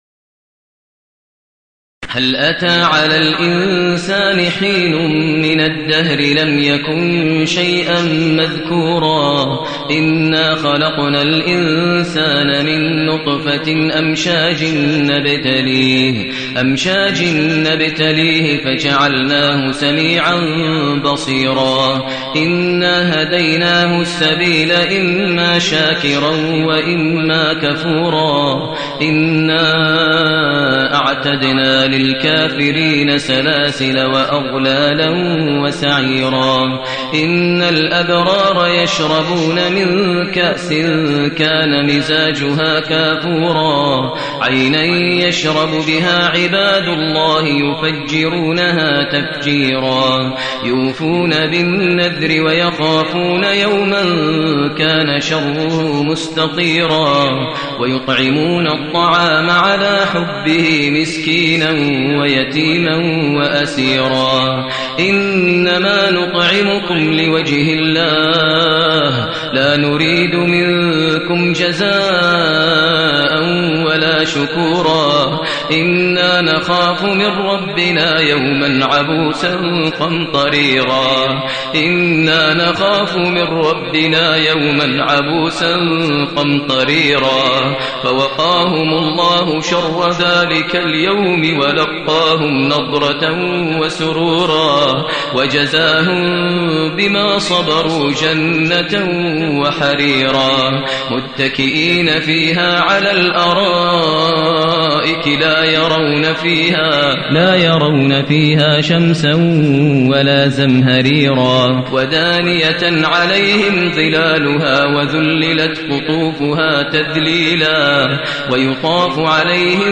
المكان: المسجد النبوي الشيخ: فضيلة الشيخ ماهر المعيقلي فضيلة الشيخ ماهر المعيقلي الإنسان